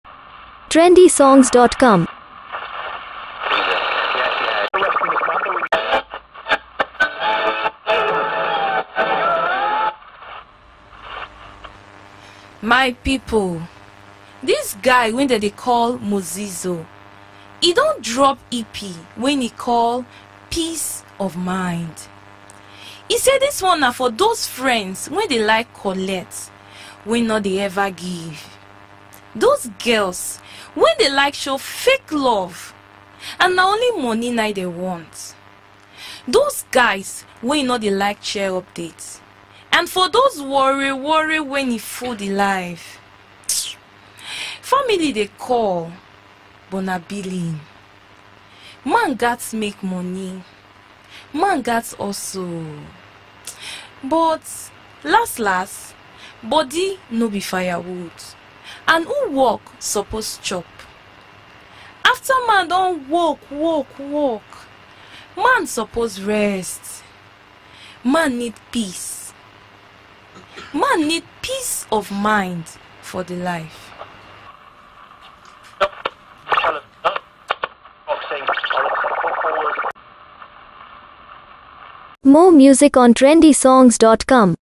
a Nigerian singer
a blend of Reggae, Dancehall & Afropop